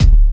Index of /90_sSampleCDs/Best Service Dance Mega Drums/BD HIP 02 B